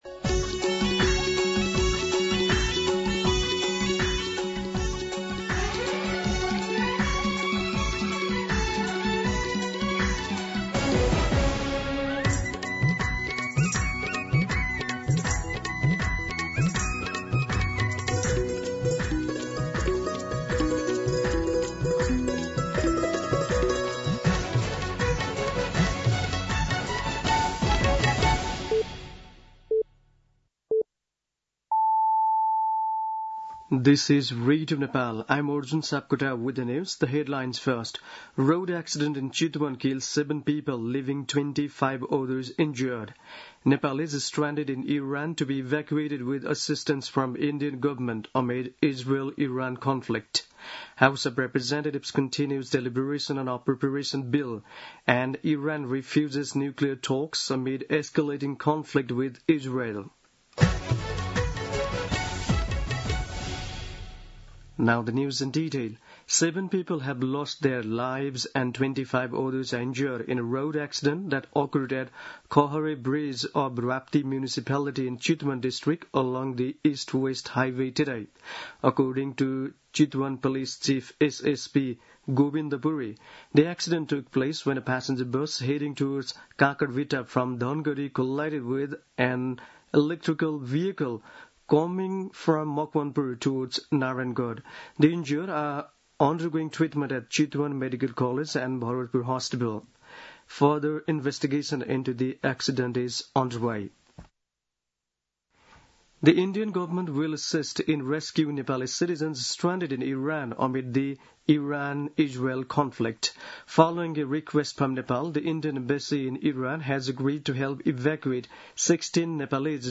An online outlet of Nepal's national radio broadcaster
दिउँसो २ बजेको अङ्ग्रेजी समाचार : ७ असार , २०८२
2pm-English-Nepali-News.mp3